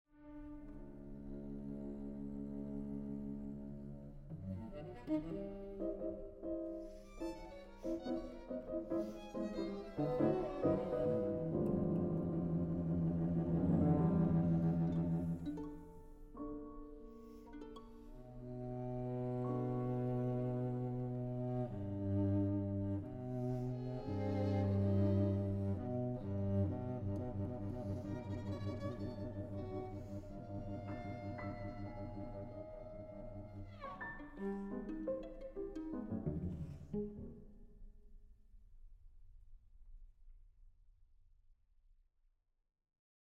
Aufnahme: Festeburgkirche Frankfurt, 2024